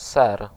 Ääntäminen
Synonyymit fromton frometon Ääntäminen France (Paris): IPA: [ɛ̃ fʁɔ.maʒ] Tuntematon aksentti: IPA: /fʁɔ.maʒ/ Haettu sana löytyi näillä lähdekielillä: ranska Käännös Ääninäyte Substantiivit 1. ser {m} Suku: m .